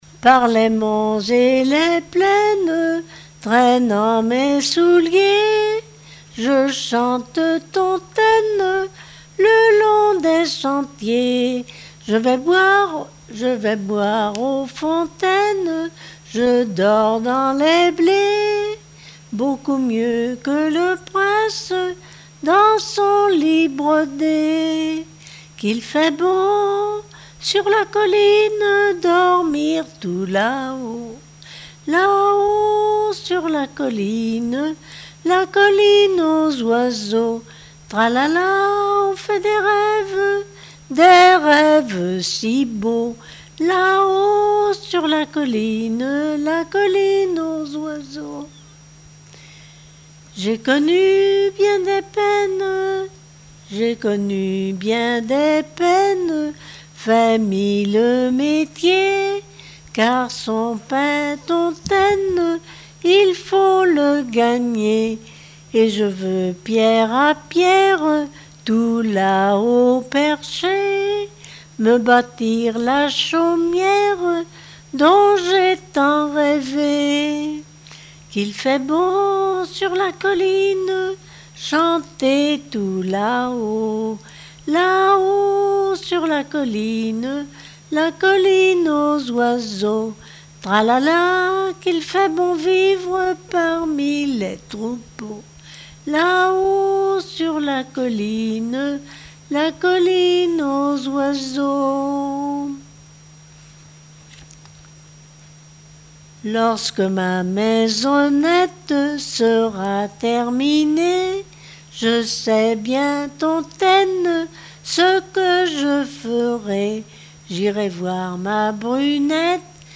Genre strophique
Répertoire de chansons populaires et traditionnelles